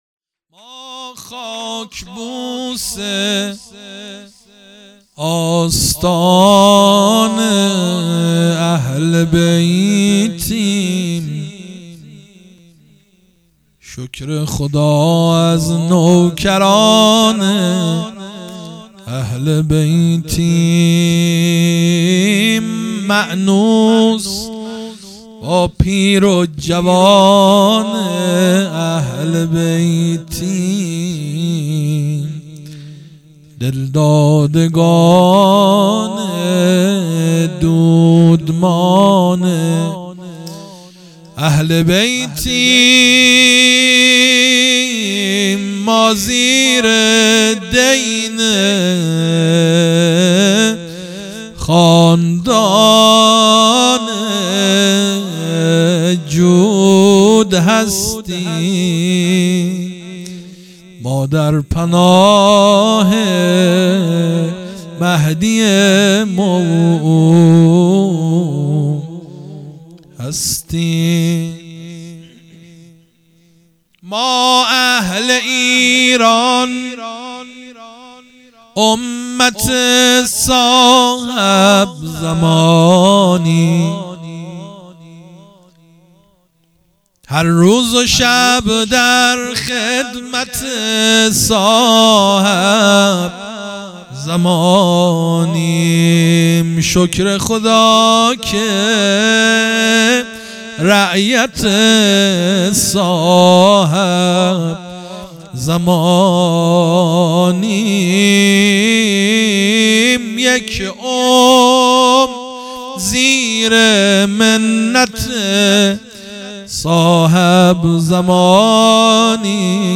مناجات | ما خاک بوس استان اهل بیتیم مداح